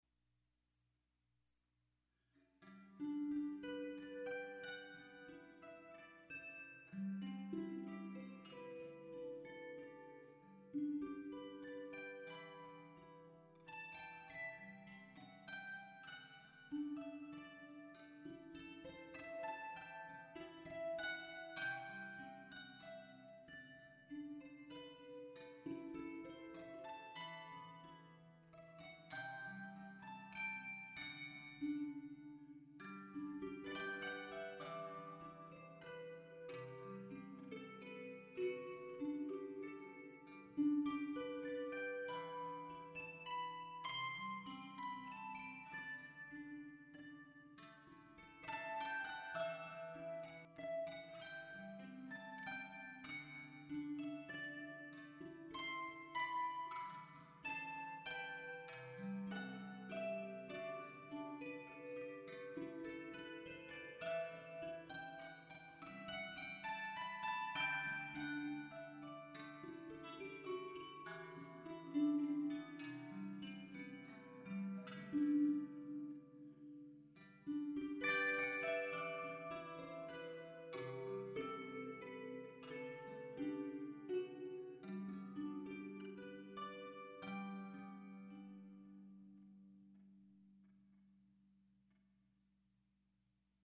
Harp Tunes